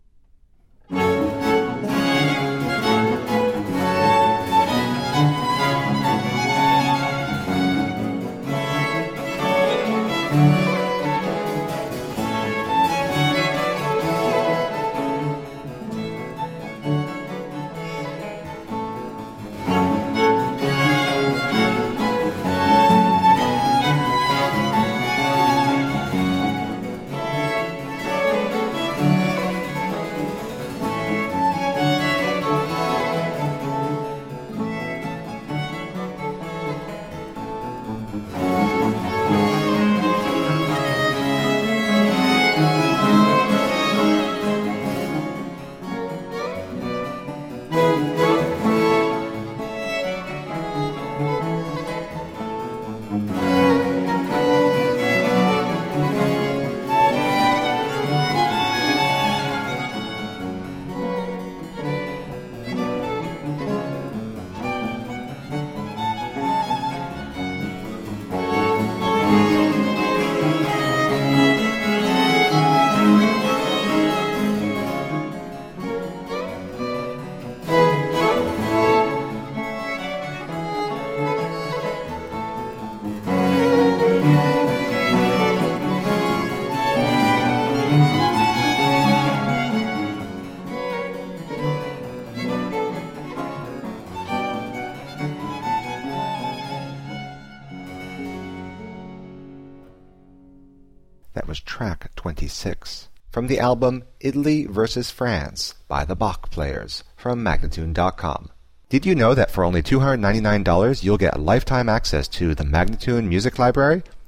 Baroque instrumental and vocal gems.
Classical, Chamber Music, Baroque, Instrumental
Harpsichord